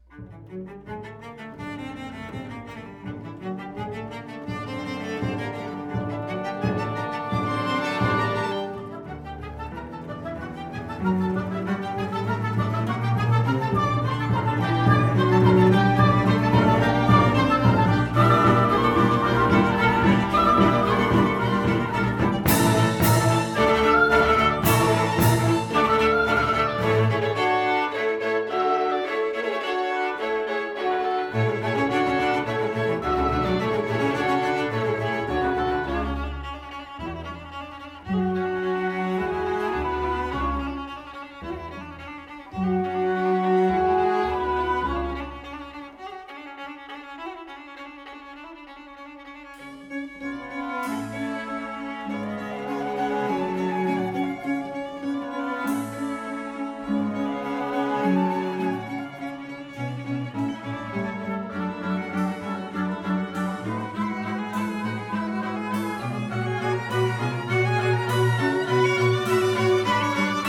俄國管弦樂名作 / 室內樂改編版本
這個室內樂版本非常棒!